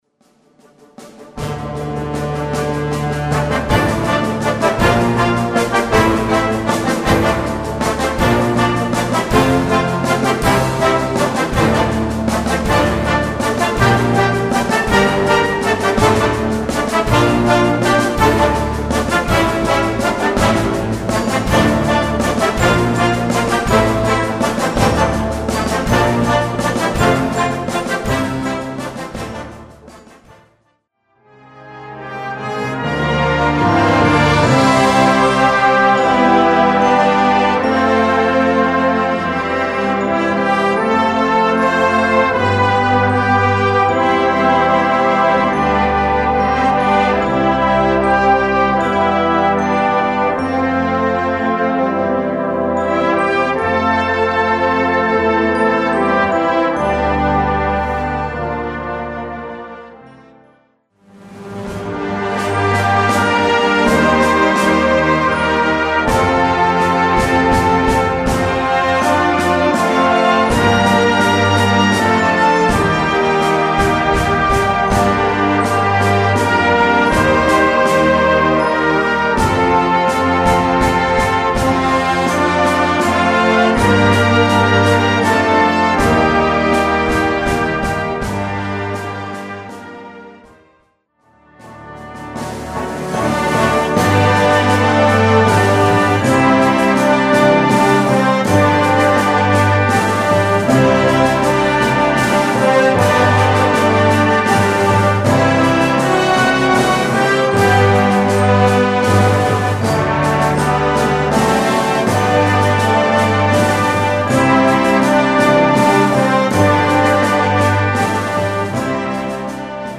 Gattung: Jugendwerk
A4 Besetzung: Blasorchester Zu hören auf